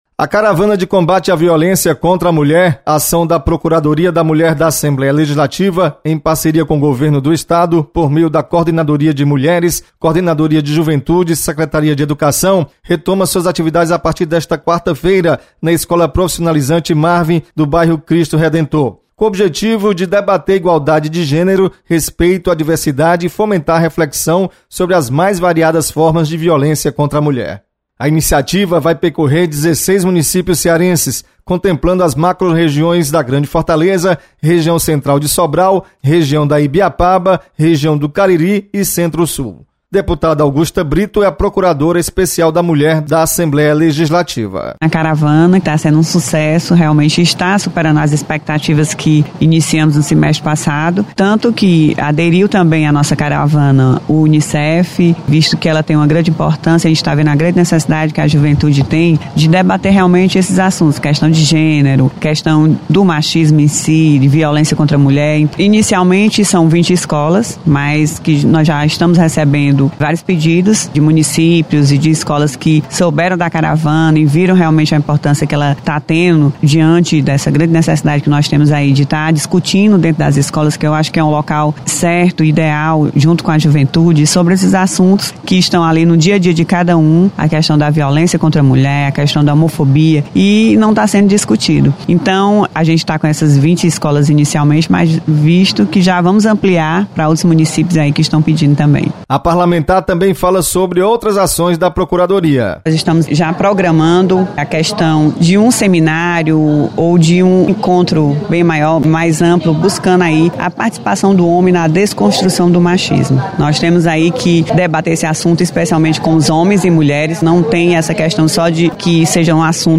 Você está aqui: Início Comunicação Rádio FM Assembleia Notícias Procuradoria da Mulher